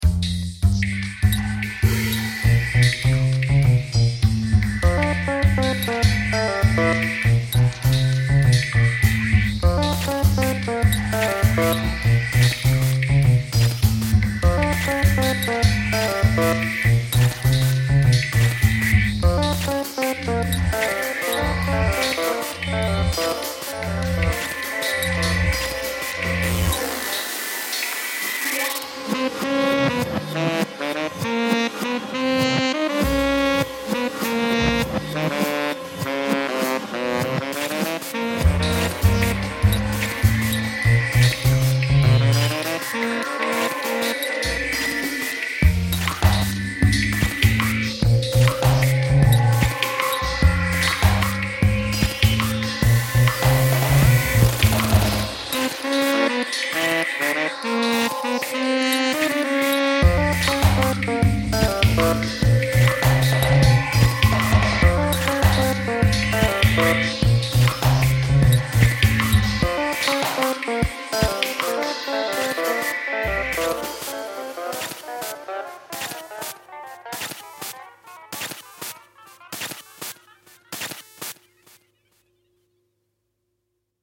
"Using the free iOS app "Beatwave", I wrote the bassline, saxophone and electric piano melodies.